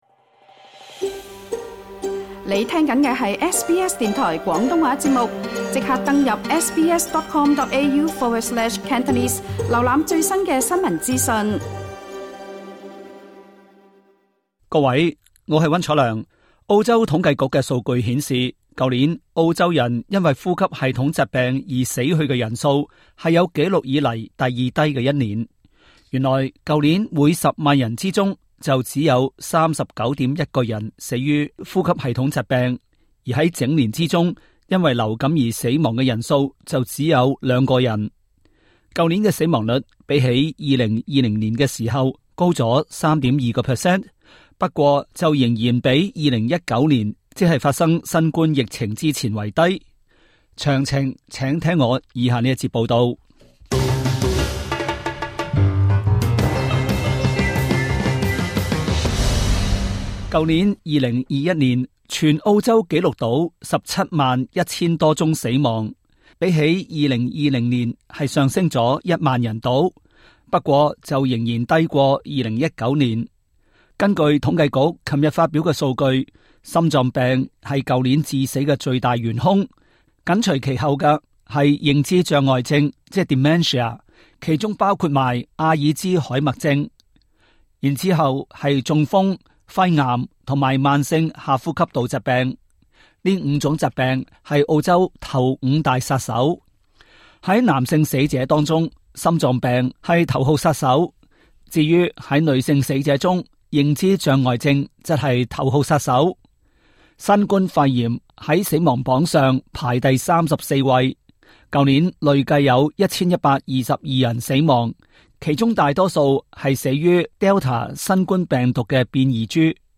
Credit: Supplied SBS廣東話節目 View Podcast Series Follow and Subscribe Apple Podcasts YouTube Spotify Download (6.08MB) Download the SBS Audio app Available on iOS and Android 去年澳洲人因為患呼吸系統疾病而死亡的人數，是有紀錄以來第二低的一年。